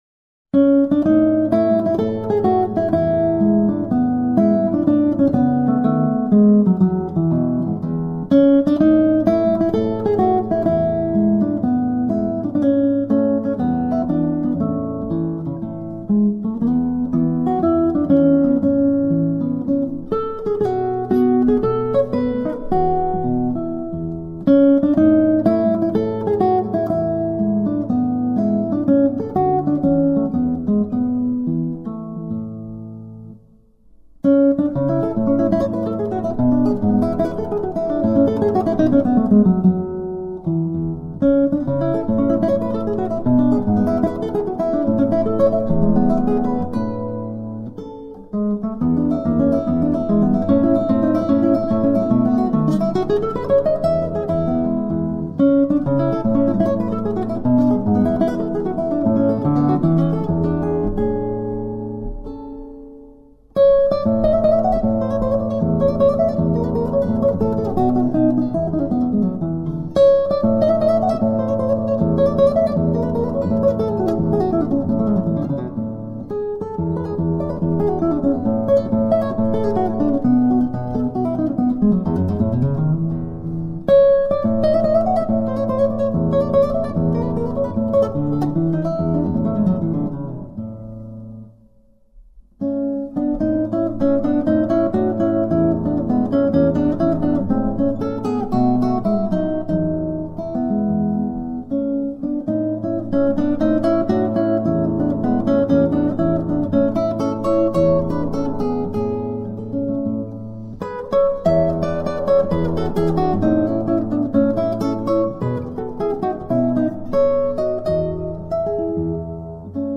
Gran Sonata. Guitarra.